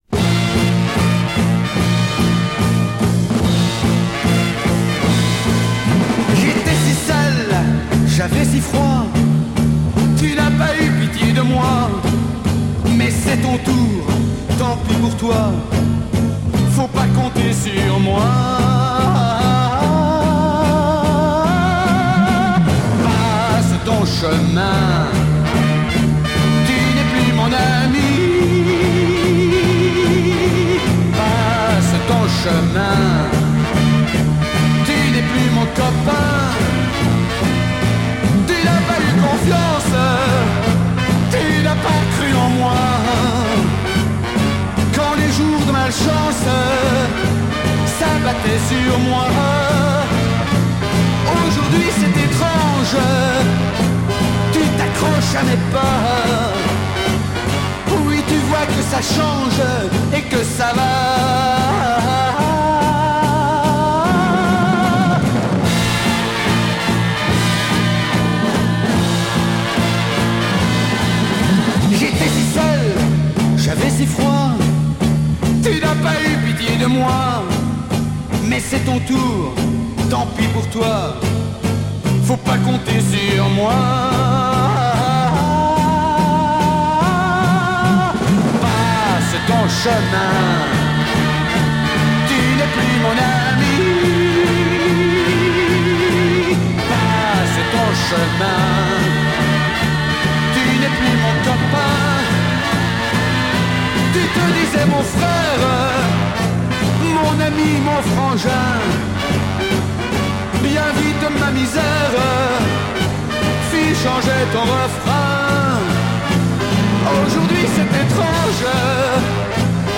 Belgium Beat Mod 7"
Belgium singer. Very good Beat mod sound, row garage sound.